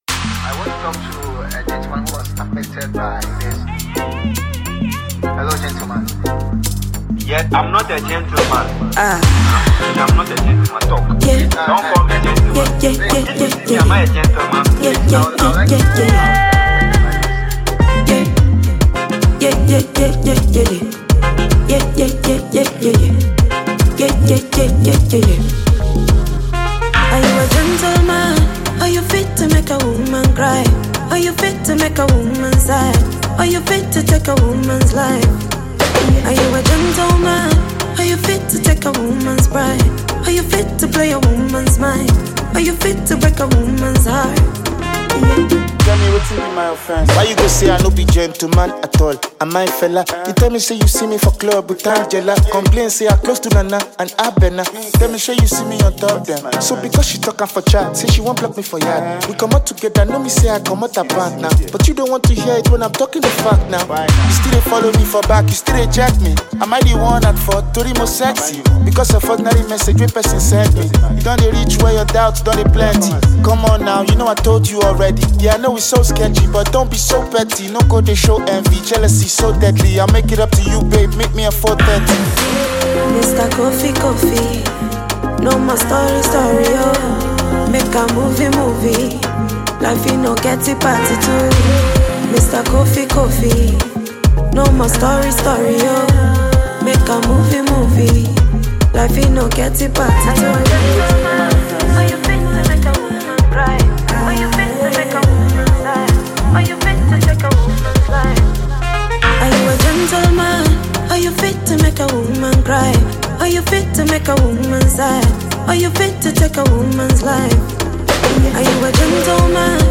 Ghanaian singer, afropop, dancehall and R&B artiste